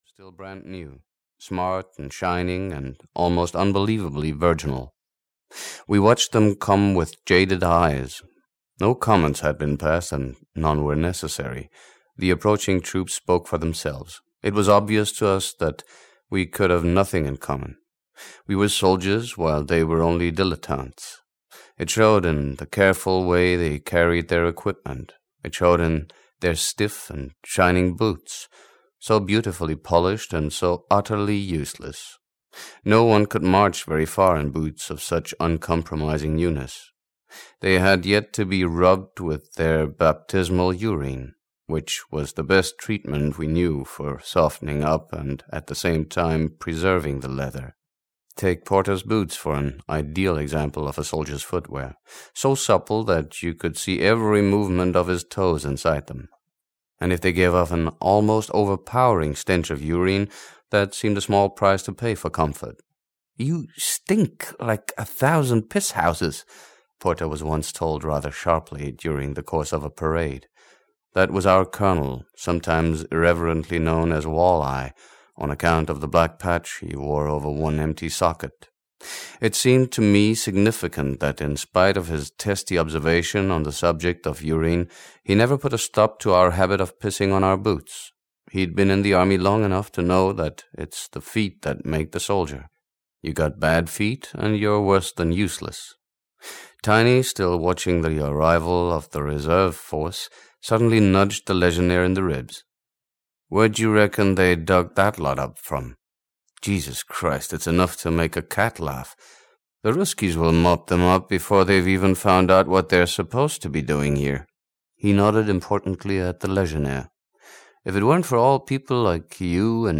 Audio knihaAssignment Gestapo (EN)
Ukázka z knihy